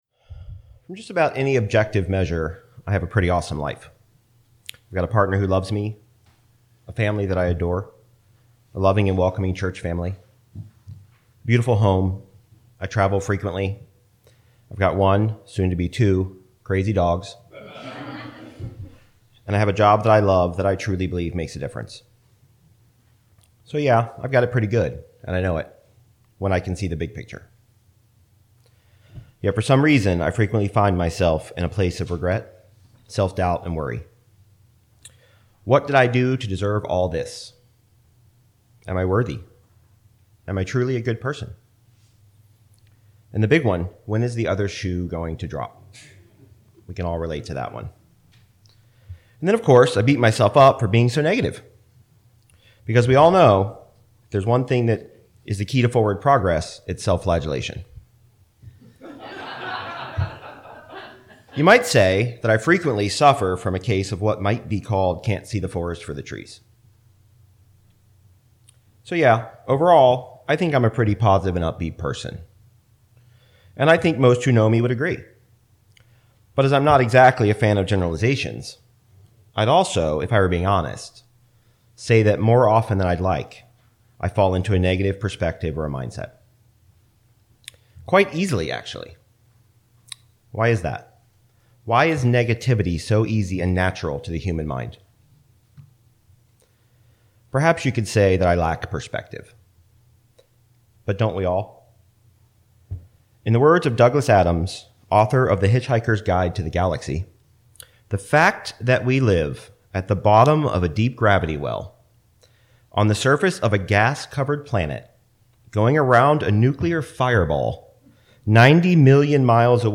This sermon explores the complex nature of human perspective and how our past experiences and confirmation bias shape our view of the world.